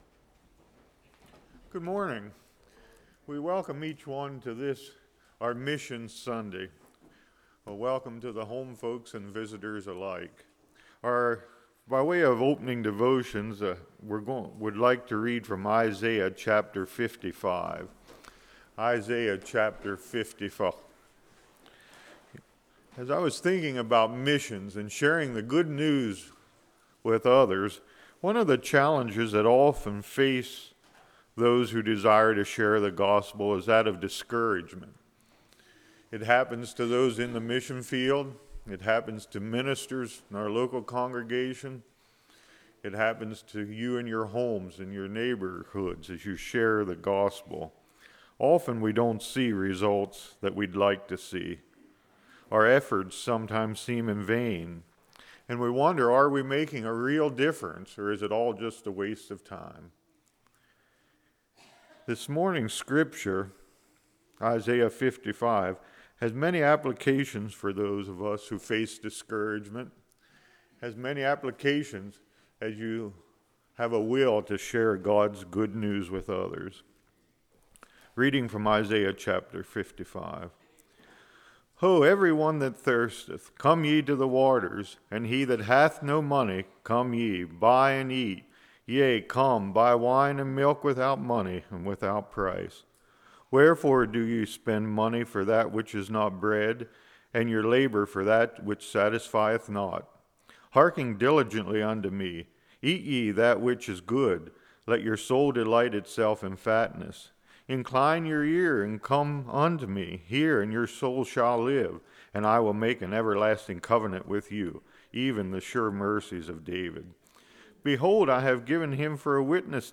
Isaiah 55:1-13 Service Type: Sunday School Completely Surrender Remember where are Remember where going « How Worship Should Change Us Father